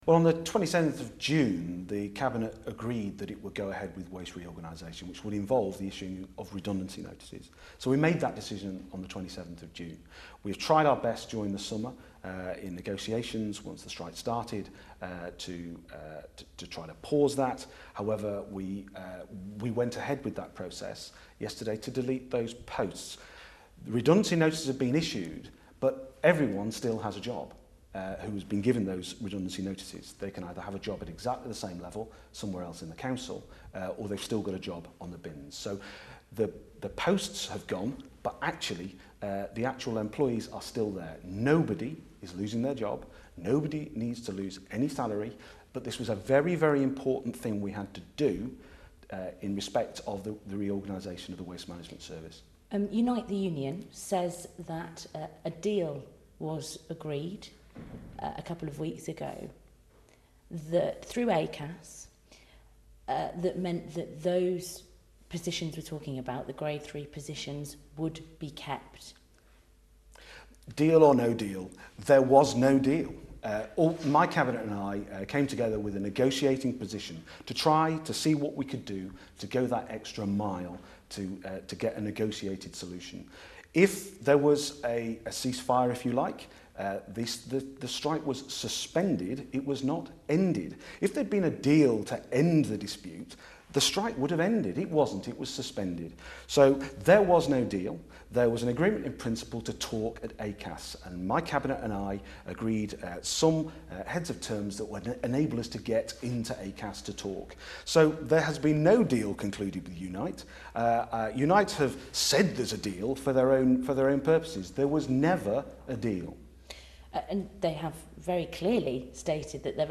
Full interview with Labour leader of Birmingham City Council following the news that redundancy notices have been issued to bin workers who have gone back on strike as a result.